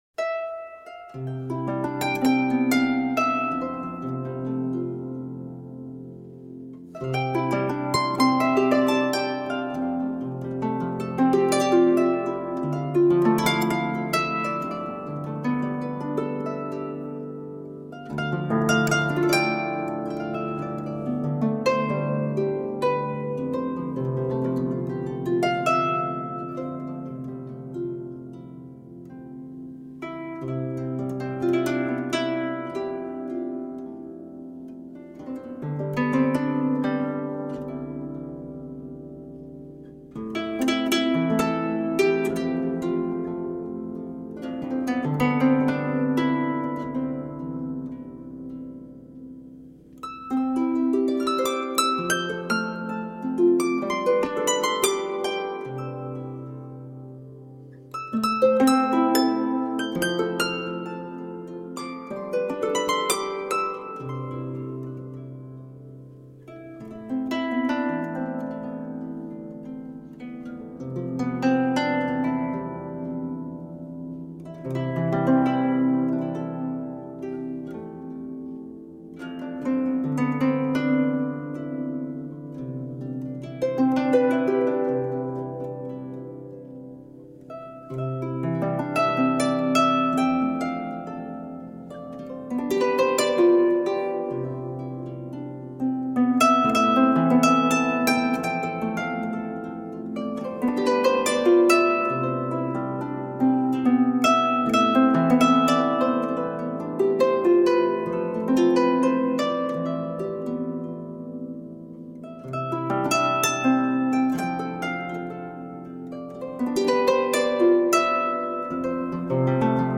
Timeless and enchanting folk music for the soul.
Tagged as: World, Folk, Christmas, Harp